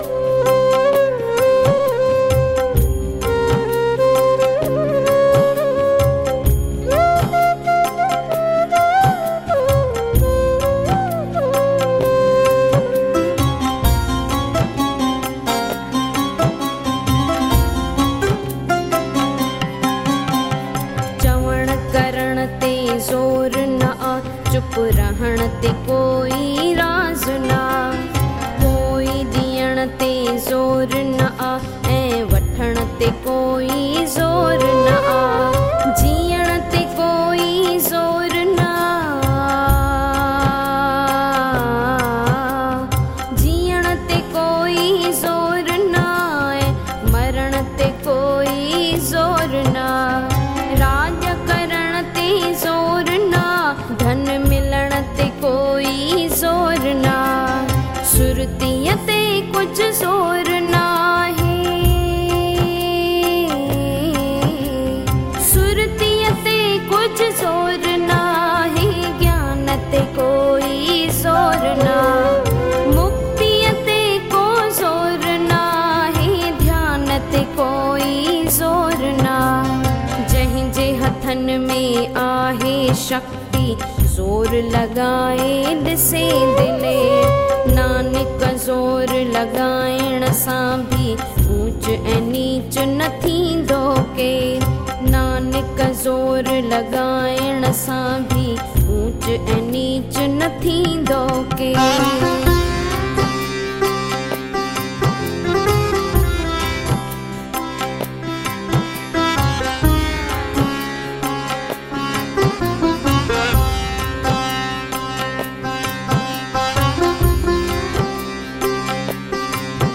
In Melodious Voice